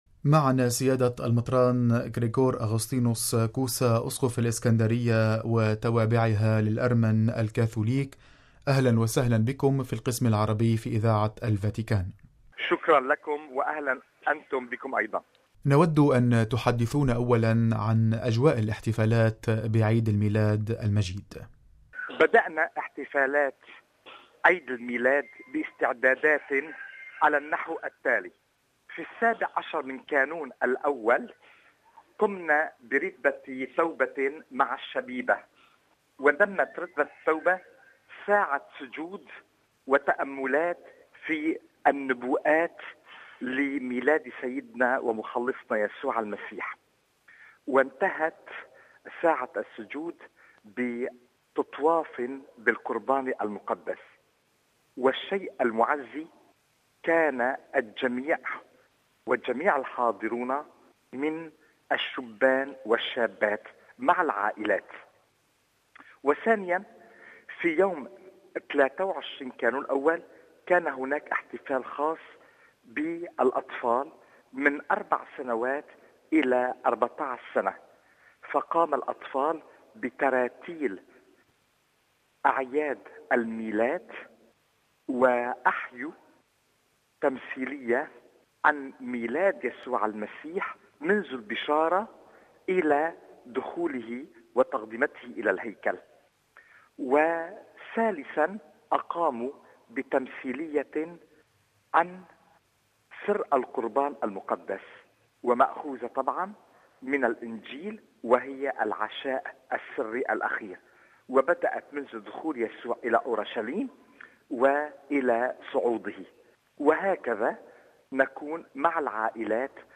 مقابلة مع أسقف الإسكندرية وتوابعها للأرمن الكاثوليك
غداة الاحتفال بعيد الميلاد المجيد أجرت إذاعتنا مقابلة مع المطران كريكور أغوسطينوس كوسا، أسقف الإسكندرية وتوابعها للأرمن الكاثوليك، حدّثنا فيها سيادته عن أجواء الاحتفالات الميلادية في أبرشيته.